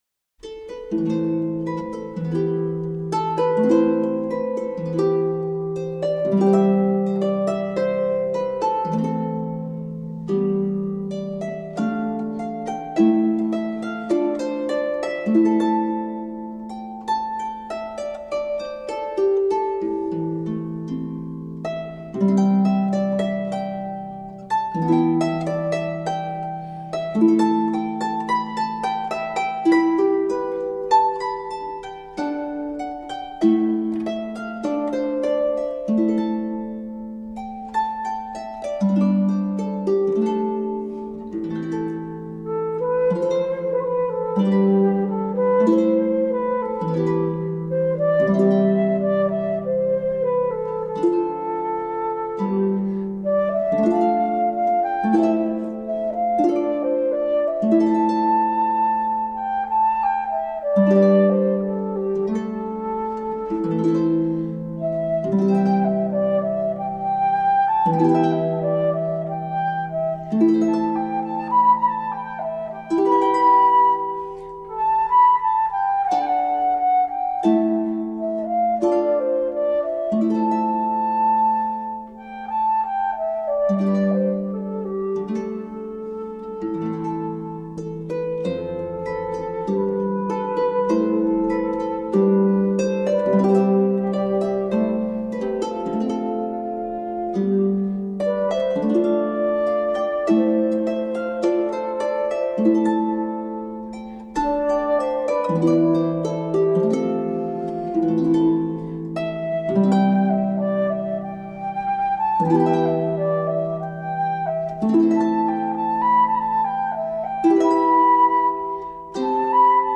Early music for healing.